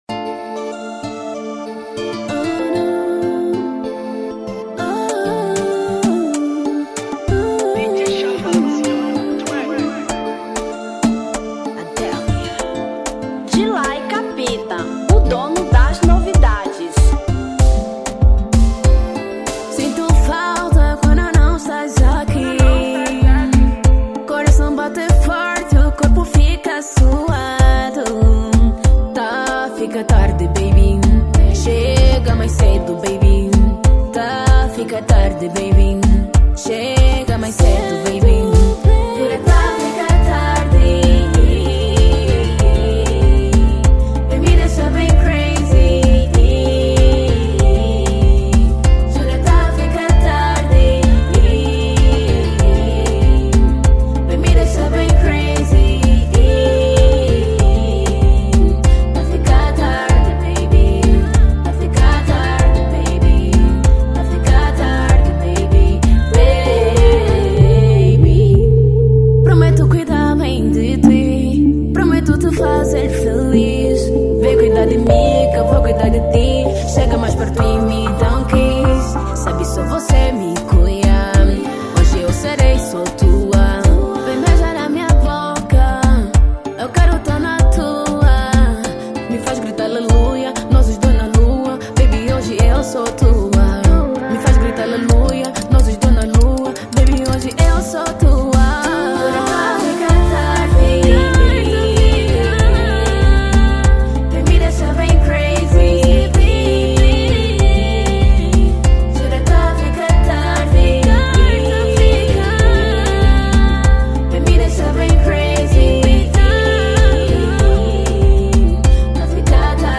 Estilo: Zouk